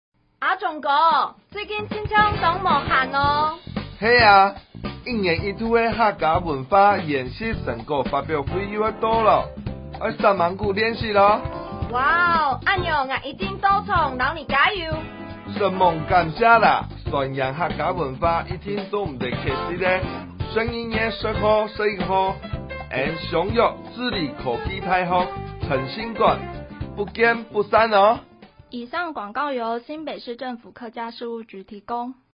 105年HAKKA新北市客家文化研習成果發表會-30秒廣播(客語) | 新北市客家文化典藏資料庫